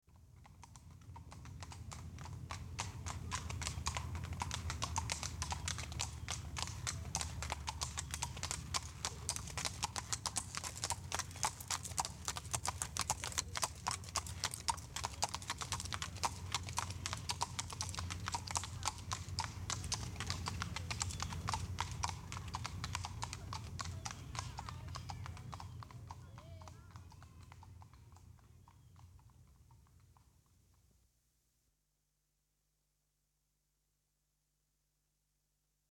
Галоп и цоканье пони